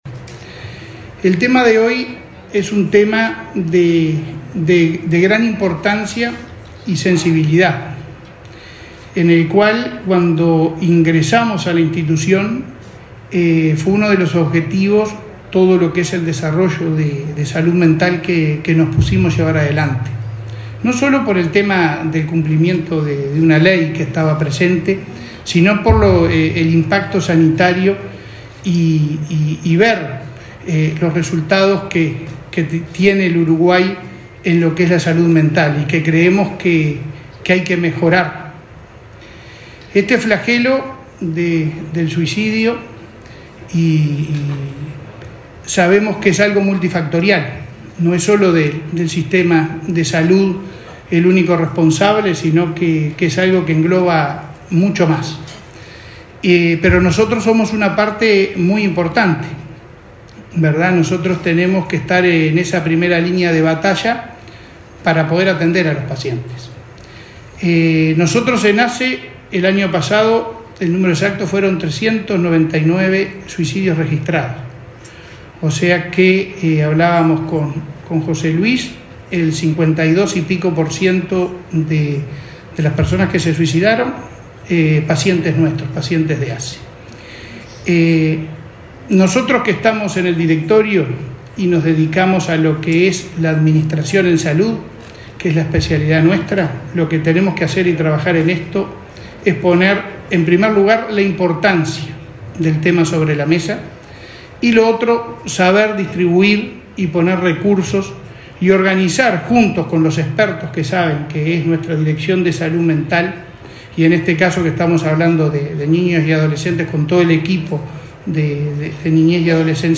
Palabras del presidente de ASSE